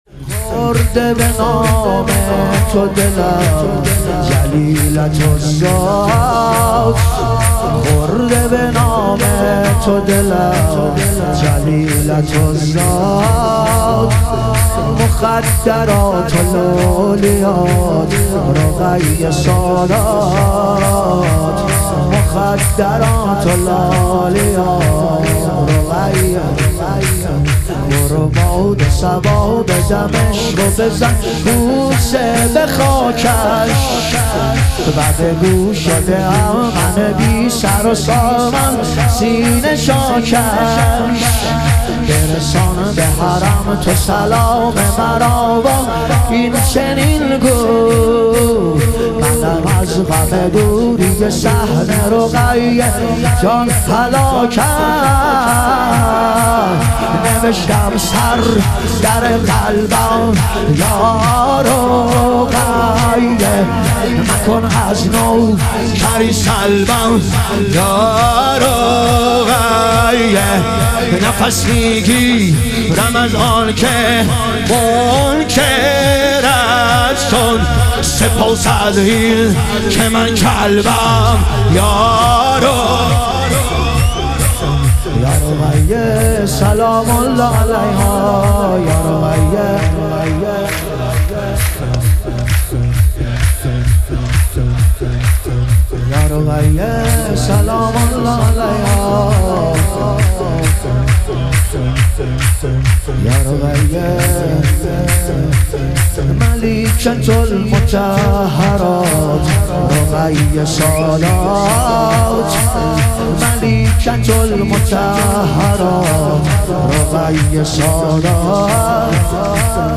ظهور وجود مقدس حضرت رقیه علیها سلام - شور